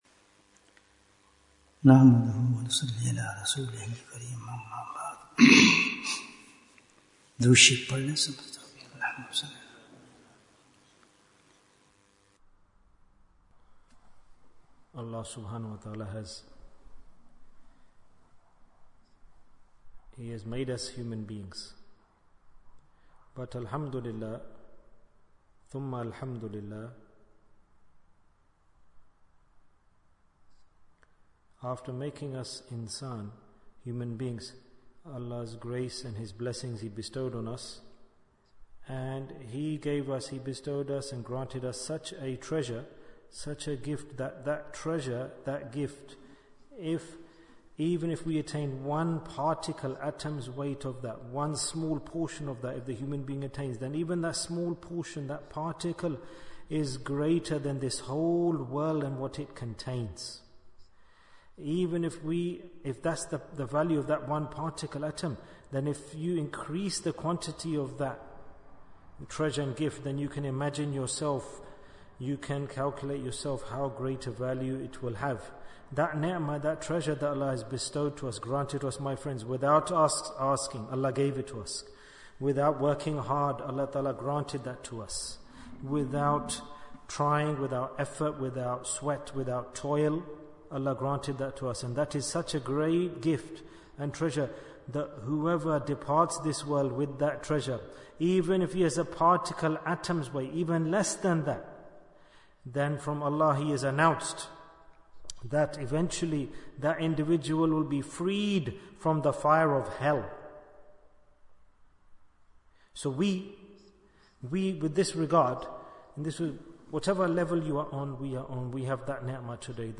These Two Points Will Make You A Wali of Allah Bayan, 72 minutes23rd July, 2024